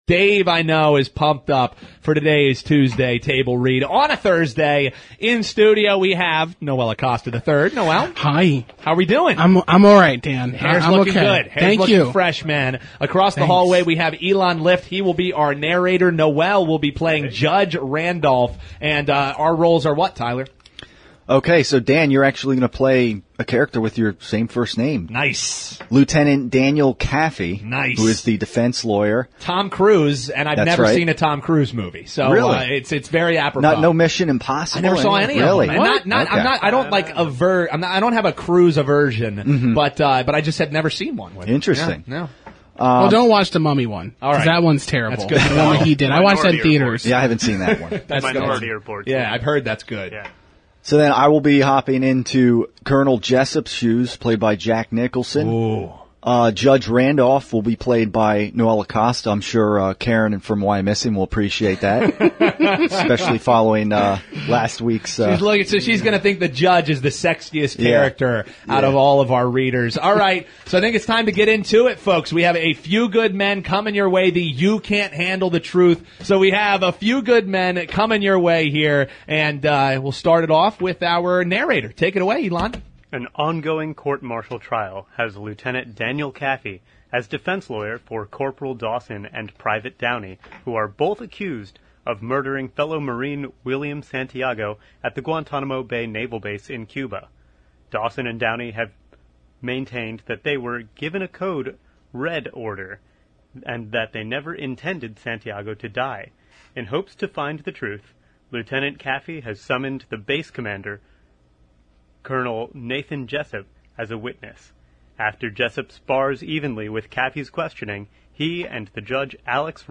A Few Good Men – Tuesday Table Read